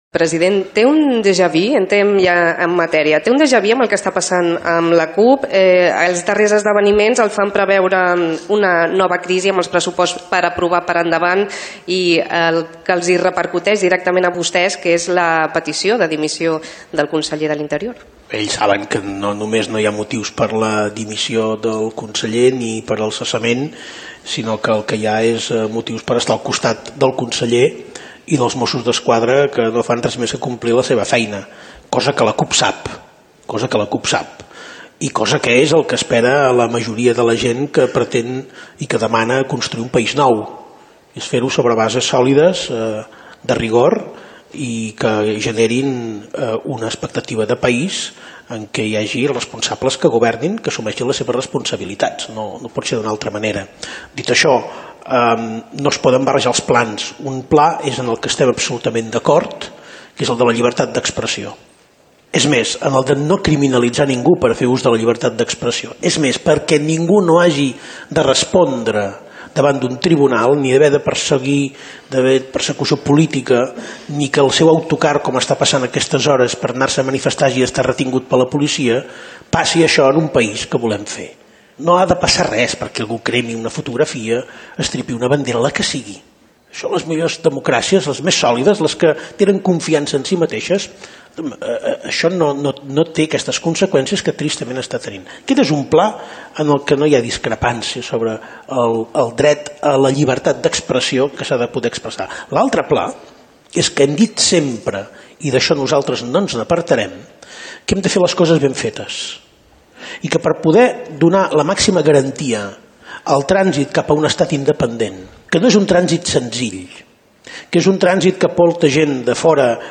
Entrevista al president de la Generalitat Carles Puigdemont sobre la relació amb la CUP (Candidatura d'Unitat Popular), el conseller d'interior, el procés cap a la independència de Catalunya, la presidenta del Parlament de Catalunya Carme Forcadell.
Informatiu